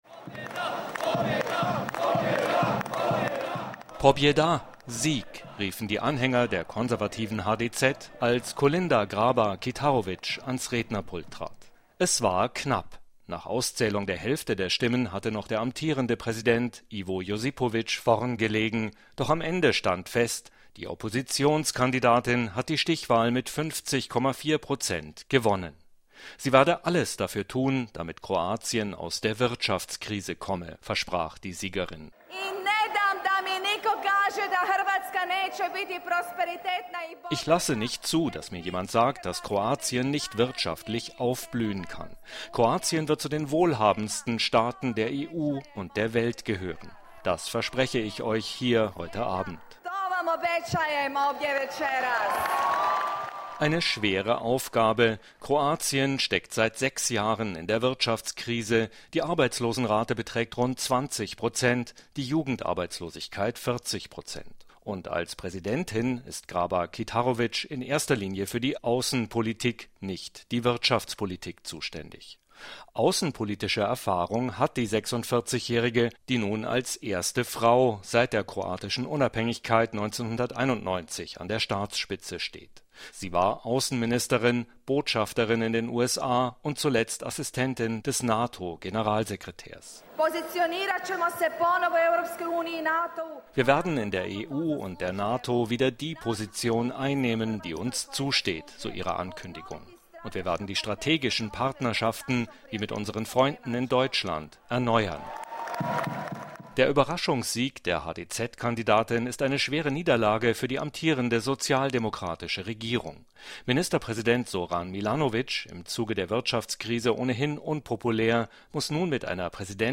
berichtet AUS Zagreb